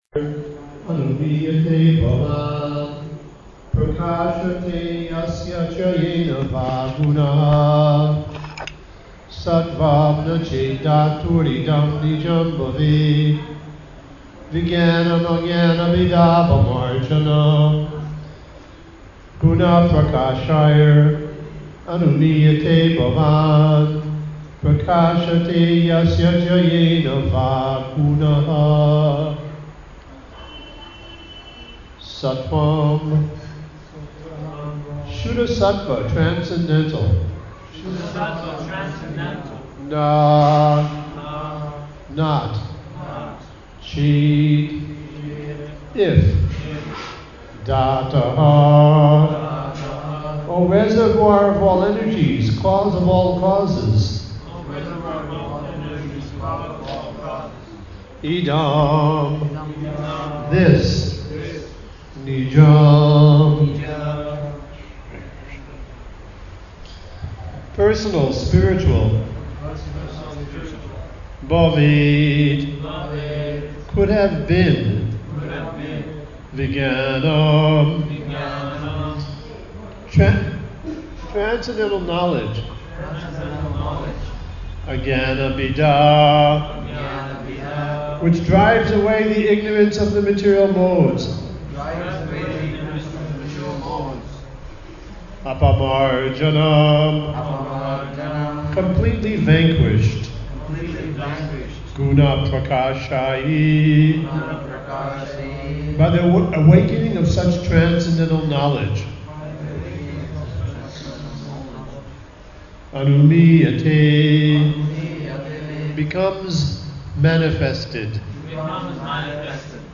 Festival Audio Lecture